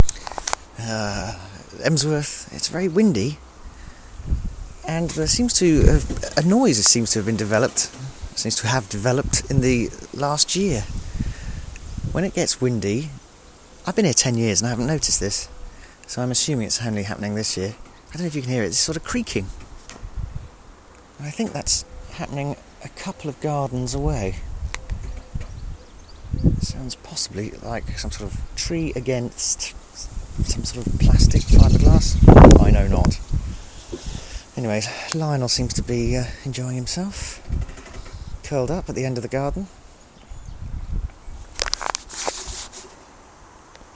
Creaking in the garden